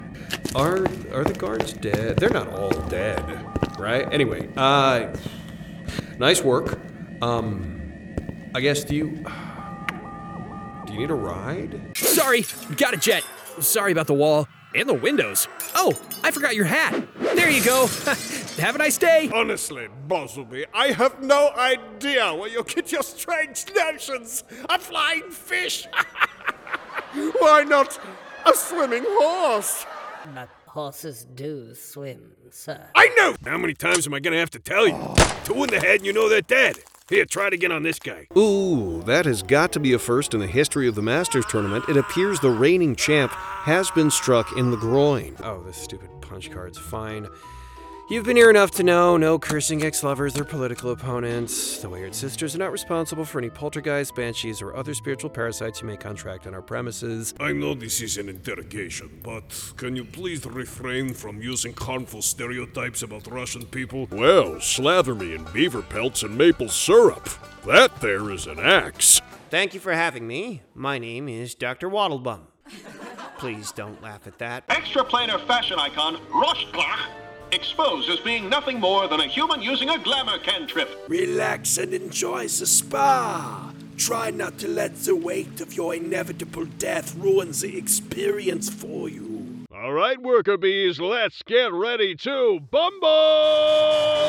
Animation Showreel
Male